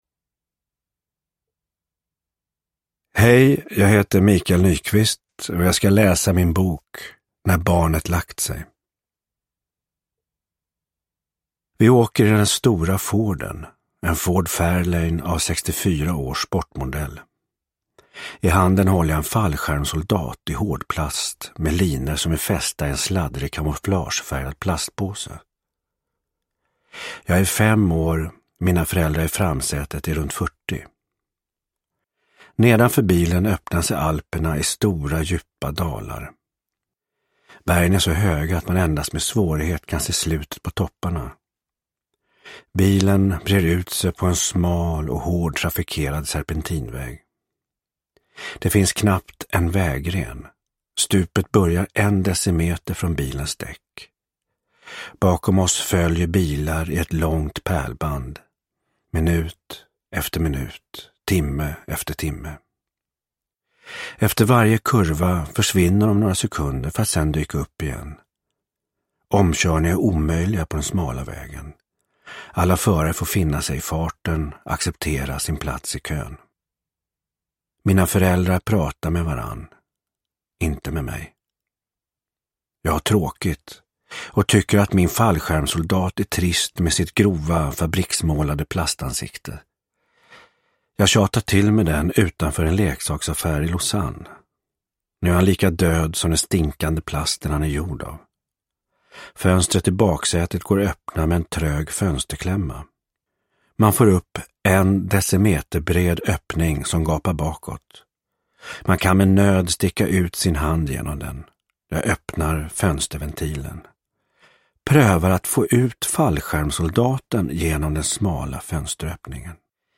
När barnet lagt sig – Ljudbok – Laddas ner
Uppläsare: Michael Nyqvist